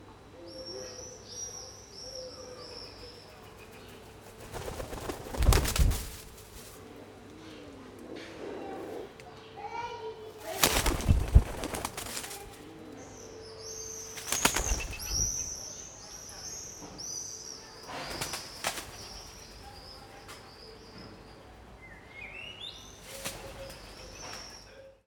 à la maison - KM140 / KM120 / SXR4+
pigeon-2.mp3